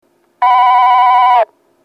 ２番線発車ベル
（軽井沢より）   軽井沢より（先頭車付近）の電子電鈴での収録です。